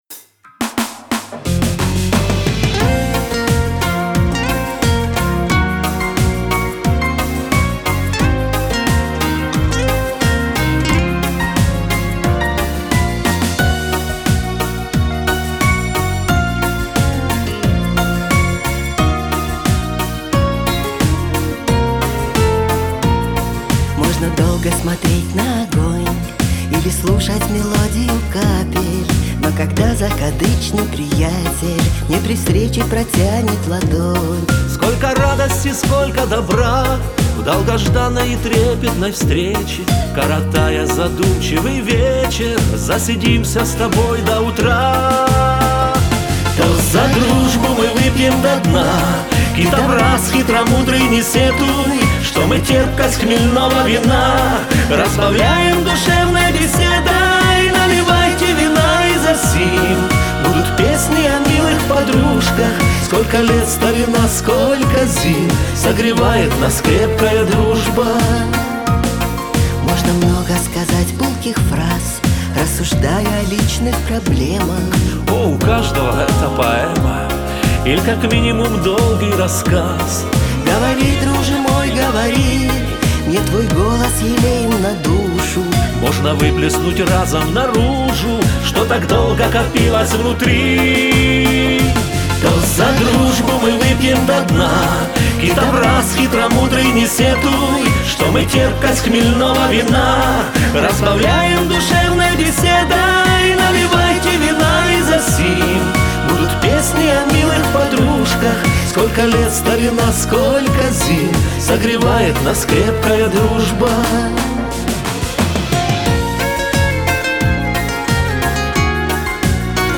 вокал, бэк-вокал
гитары